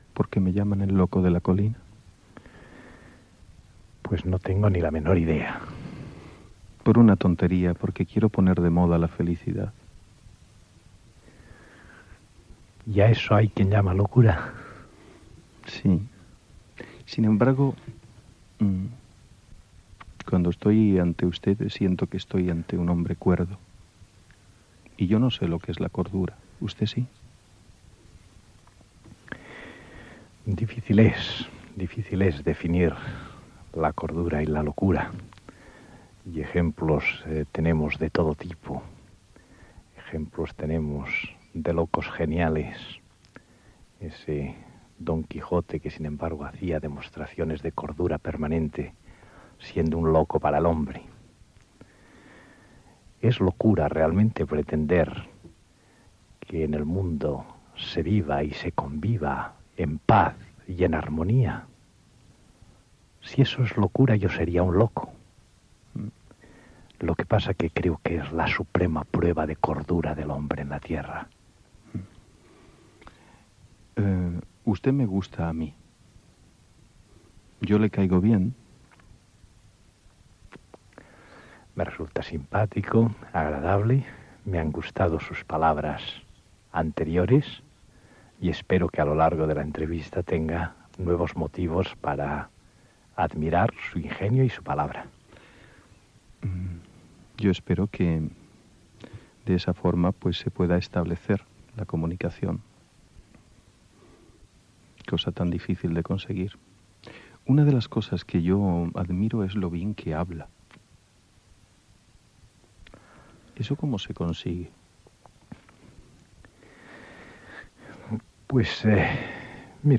Entrevista al polític Landelino Lavilla, que el 18 de febrer de 1983 havia dimitit com a líder del partit Unión de Centro Democrático (UCD)
Entreteniment